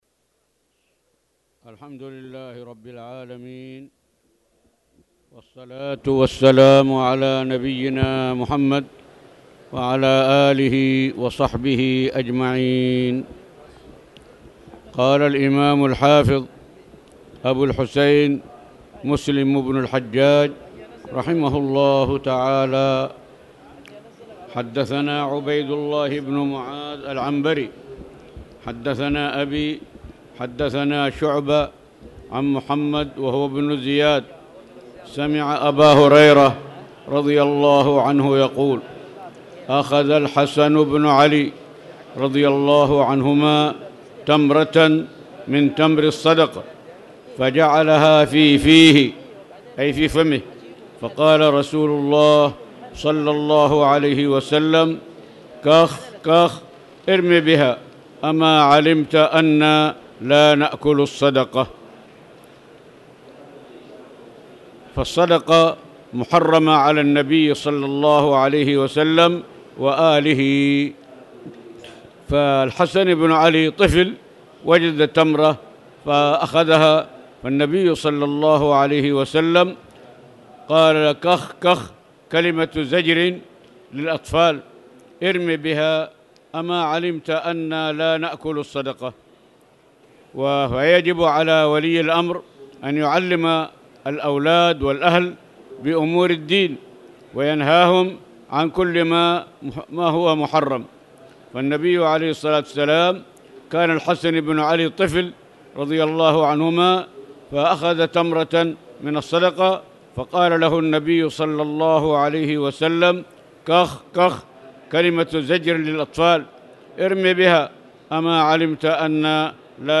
تاريخ النشر ١٥ رمضان ١٤٣٧ هـ المكان: المسجد الحرام الشيخ